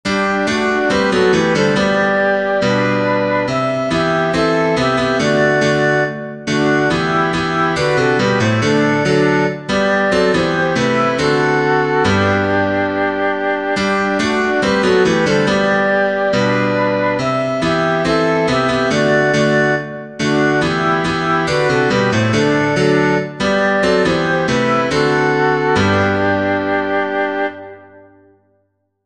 Makundi Nyimbo: Zaburi